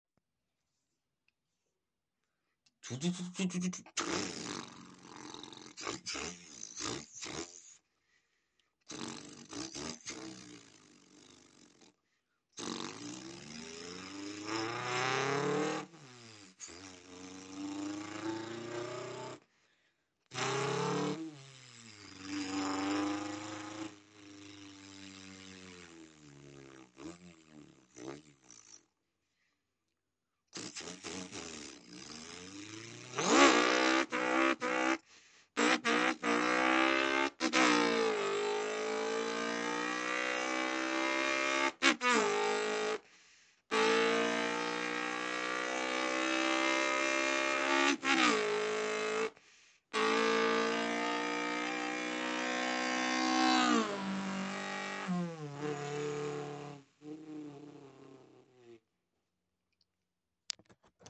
Follow For More Car Sounds♥✊ Sound Effects Free Download